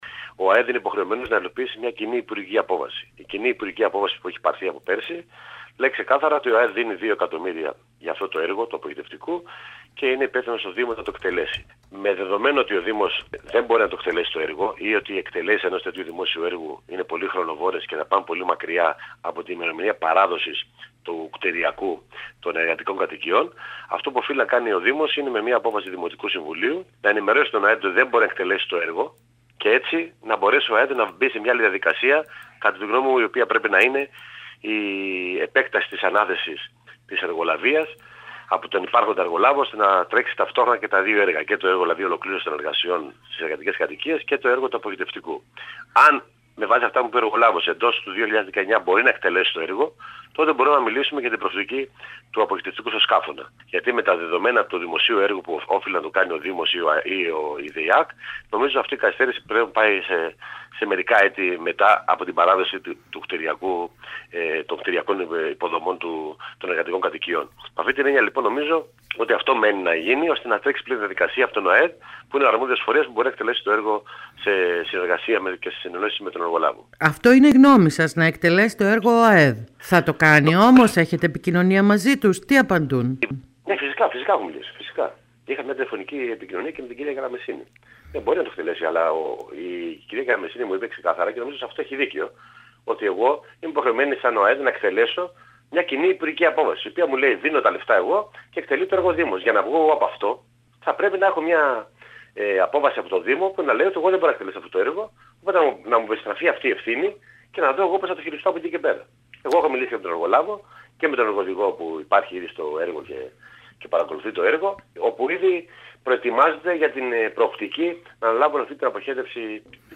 Στην εκτέλεση από τον ΟΑΕΔ της κατασκευής του αποχετευτικού αγωγού των νέων εργατικών κατοικιών στον Άη Γιάννη, φαίνεται να οδηγούνται τα πράγματα, σύμφωνα με τον Βουλευτή του ΣΥΡΙΖΑ Κώστα Παυλίδη. Όμως, όπως ανέφερε ο βουλευτής, μιλώντας σήμερα στην ΕΡΤ Κέρκυρας, για να ακυρωθεί η ΚΥΑ που προέβλεπε ότι ο ΟΑΕΔ χρηματοδοτεί και ο Δήμος εκτελεί το έργο, πρέπει πρώτα ο Δήμος να δηλώσει ότι δεν μπορεί να κάνει το έργο και μάλιστα με τον επισημότερο τρόπο, δηλαδή με απόφαση δημοτικού συμβουλίου.